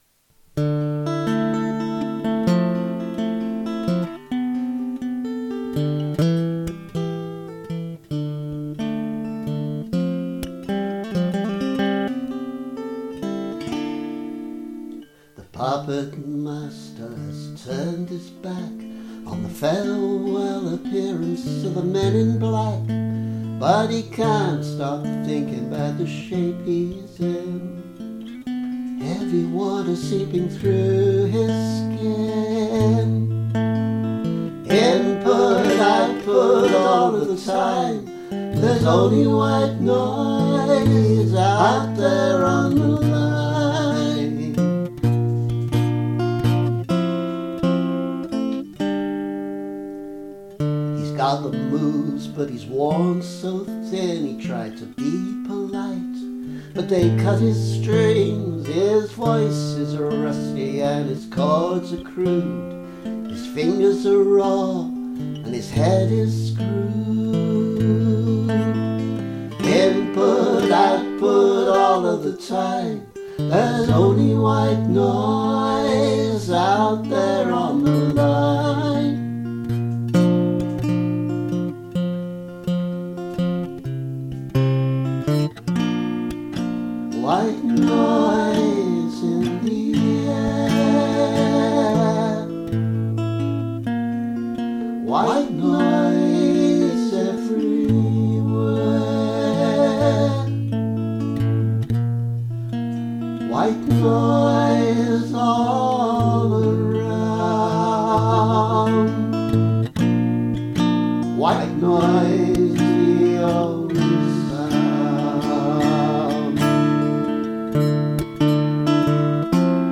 White Noise – demo
:) I think this will probably have a fairly synth-y feel when I’ve learned it properly, but while it obviously needs polishing, this will be the basic shape.